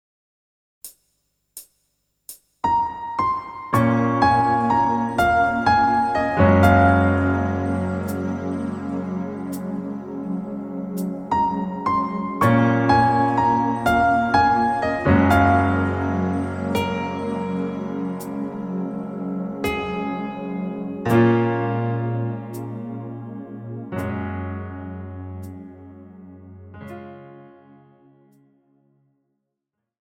KARAOKE/FORMÁT:
Žánr: Pop
BPM: 126
Key: Bbm